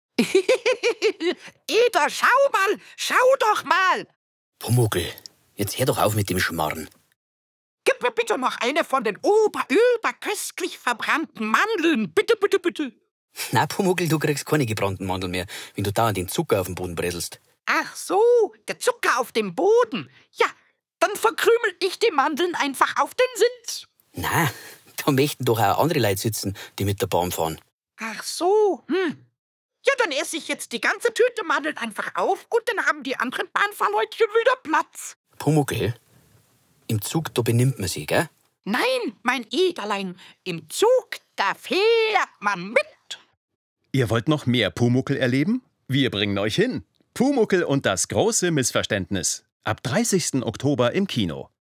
Pumuckl, der freche Kobold mit dem roten Haarschopf, hat es sich nämlich in den Zuglautsprechern gemütlich gemacht – inklusive Schabernack, Gekicher und Anspielungen auf gebrannte Mandeln.
Die rund 20 Sekunden lange Durchsage beginnt mit dem typischen Ding-Dong, dann folgt ein kurzer Dialog zwischen Pumuckl und seinem Meister Eder – ganz in der Tradition der Kultserie.
So klingt die Pumuckl-Durchsage